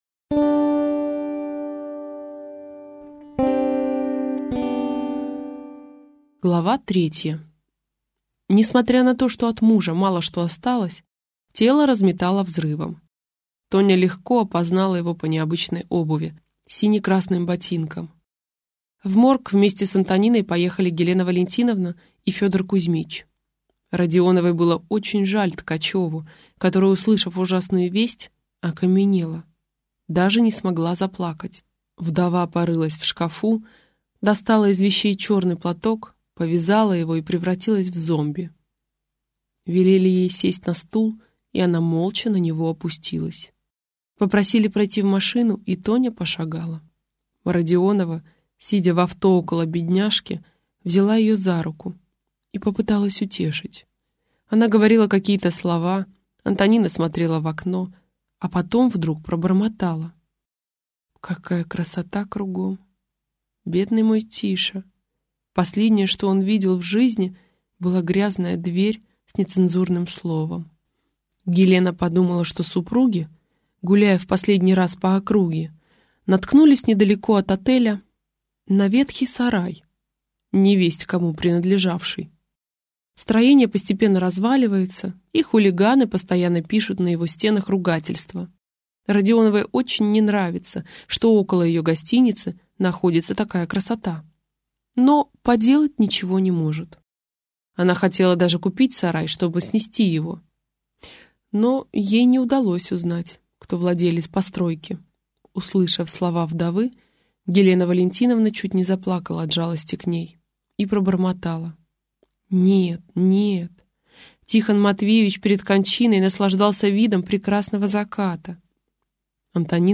Прослушать фрагмент аудиокниги Страсти-мордасти рогоносца Дарья Донцова Произведений: 293 Скачать бесплатно книгу Скачать в MP3 Скачать в TXT Скачать в PDF Скачать в EPUB Вы скачиваете фрагмент книги, предоставленный издательством